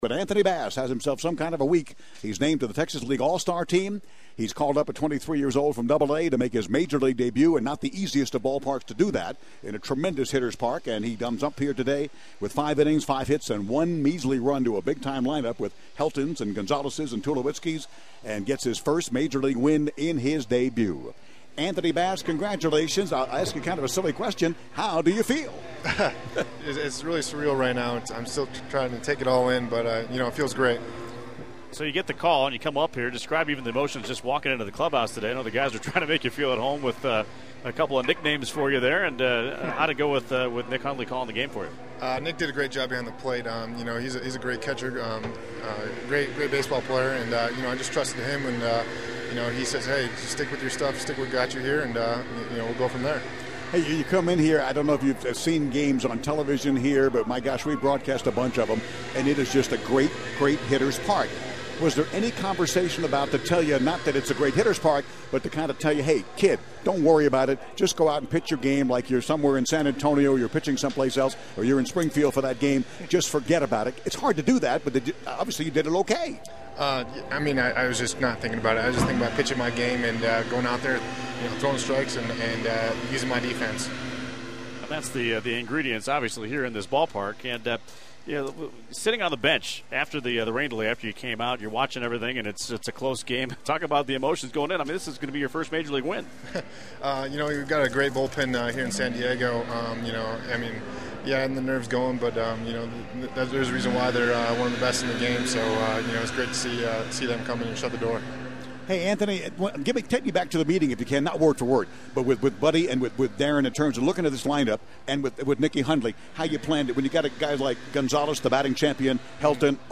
postgame interview on XX 1090